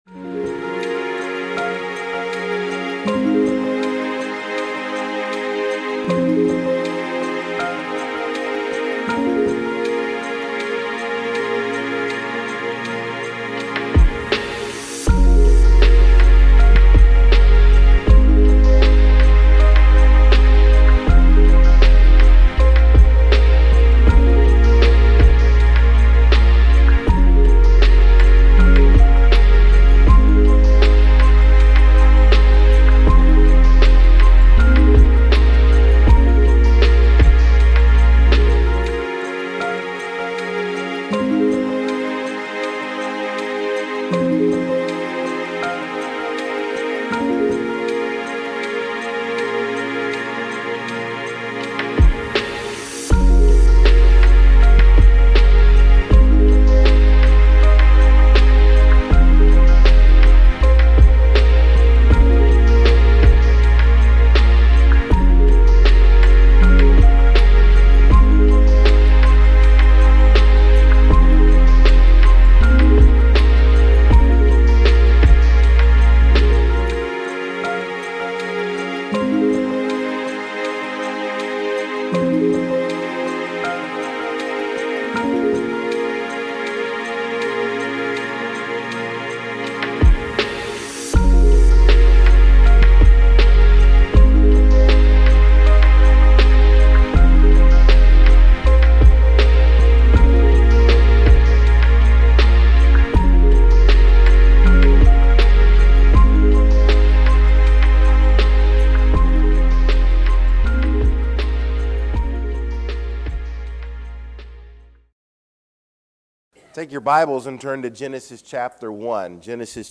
Sermons | Rocky Point Baptist Church